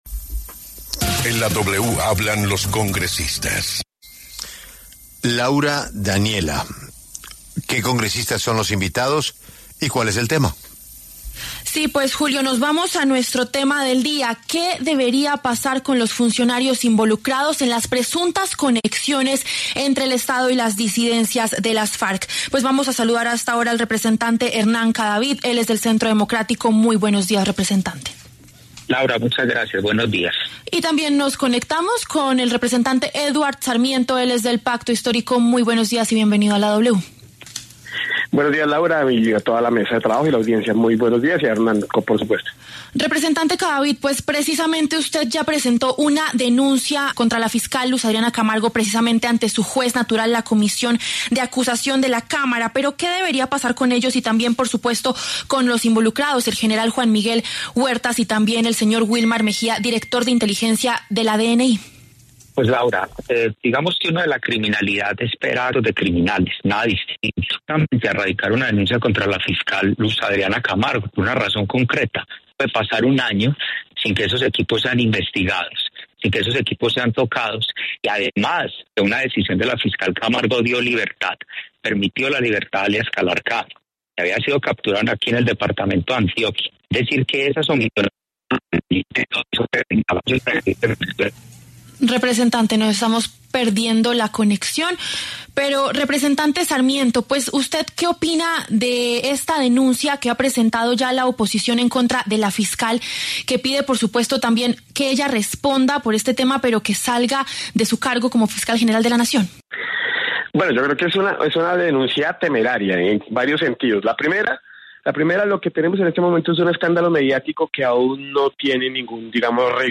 Los representantes Hernán Cadavid, del Centro Democrático, y Eduard Sarmiento, del Pacto Histórico, pasaron por los micrófonos de La W para hablar sobre el informe de una infiltración de las disidencias Farc en el Estado.